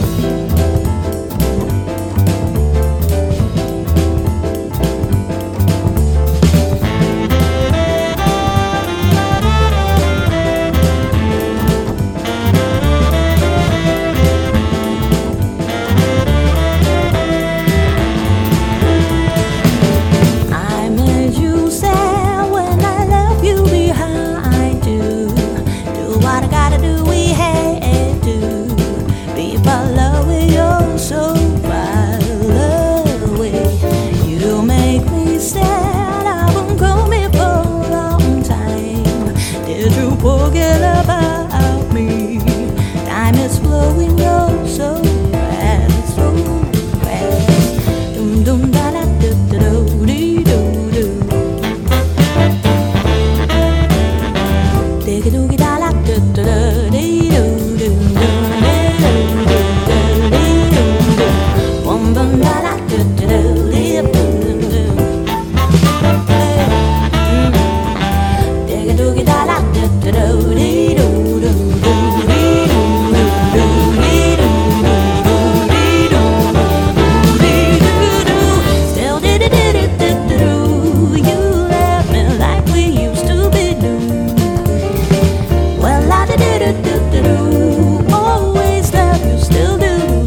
BREAKBEATS/HOUSE / CLUB JAZZ / JAPANESE CLUB
持ち味を発揮した流れるようなピアノの響きにウットリさせられる素晴らしい一曲！
音数の少なさが非常に心地よいダウンテンポ/ディープ・ハウスなミックス！